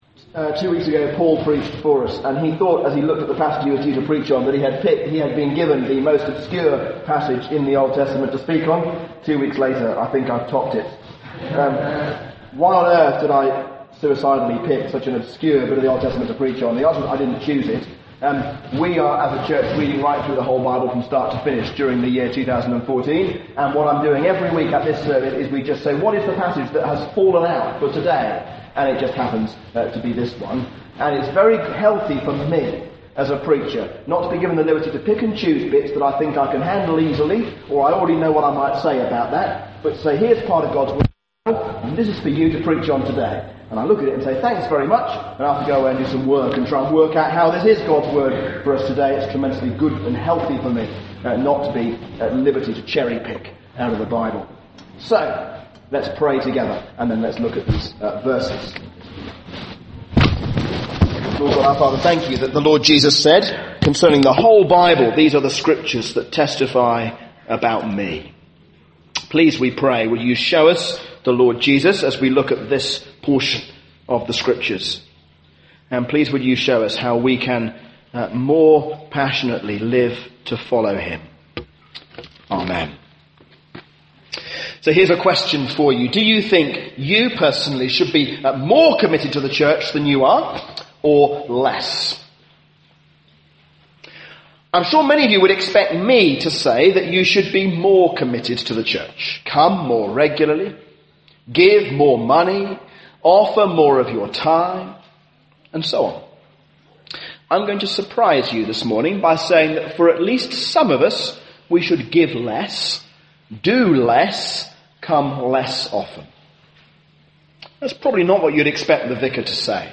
A sermon on Numbers 30